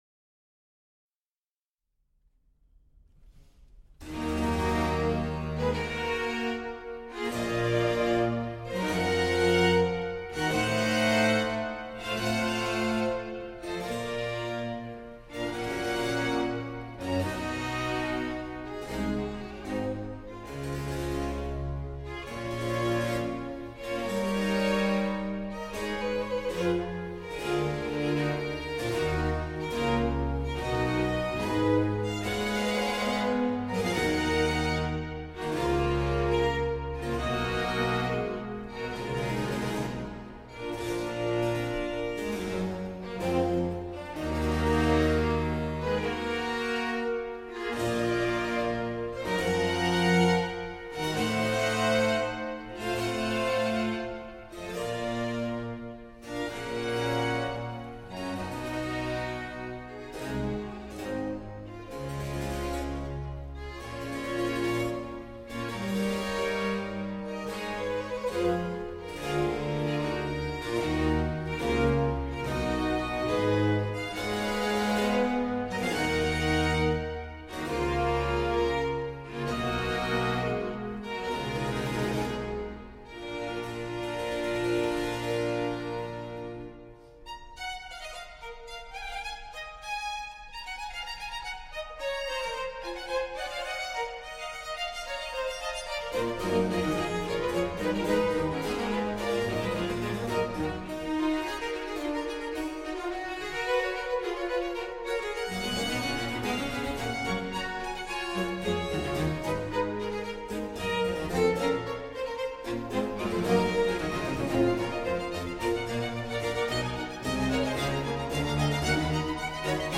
Oratorio
Group: Classical vocal
Handel - Messiah - 01 Sinfonia